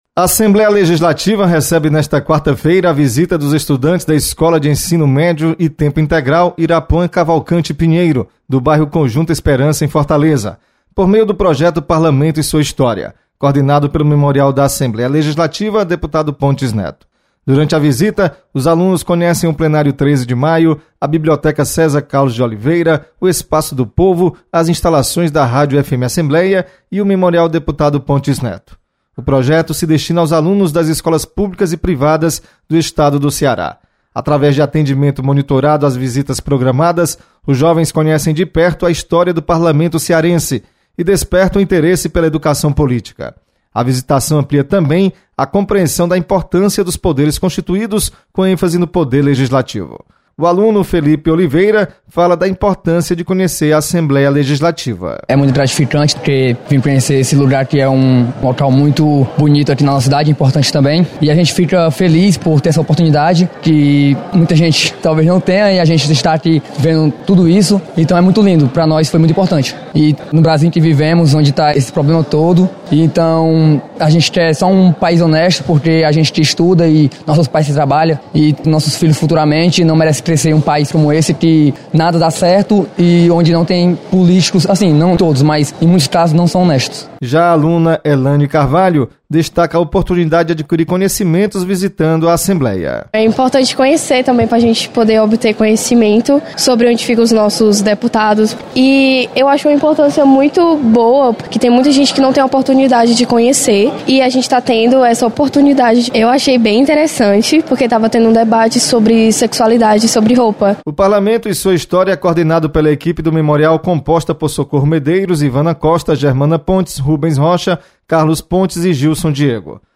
Memorial Pontes Neto recebe alunos do Conjunto Esperança. Repórter